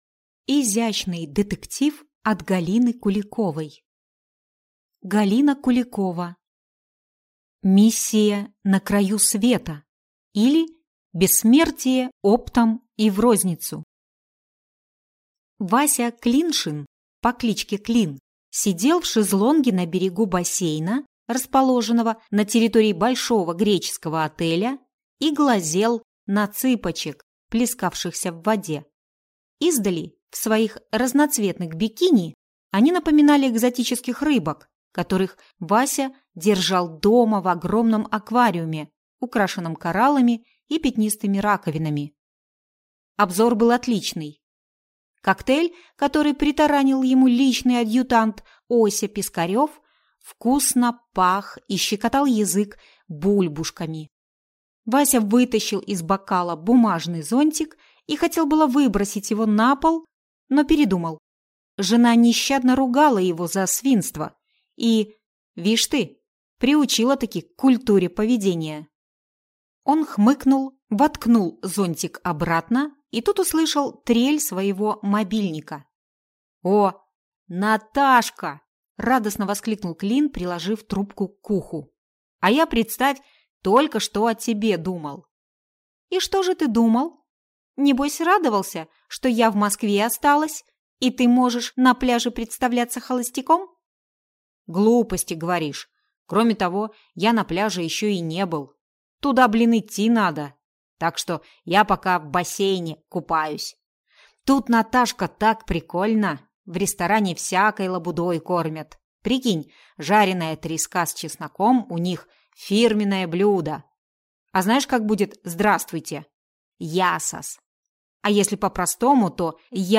Аудиокнига Миссия на краю света, или Бессмертие оптом и в розницу | Библиотека аудиокниг